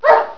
snd_10045_Dog.wav